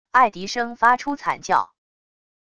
爱迪生发出惨叫wav音频